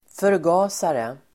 Folkets service: förgasare förgasare substantiv, (carburetor [US]) , carburettor Uttal: [förg'a:sare] Böjningar: förgasaren, förgasare, förgasarna Definition: anordning i motor där bensin blandas med luft